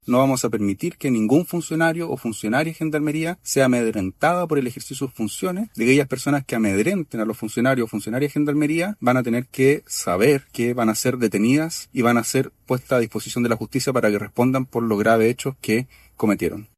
Por su parte, el ministro de Justicia y Derechos Humanos, Jaime Gajardo, fue enfático en señalar que el gobierno no va a permitir que este tipo de situaciones, que ponen en riesgo a funcionarios de Gendarmería y a sus familias, se produzcan.